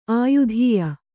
♪ āyudhīya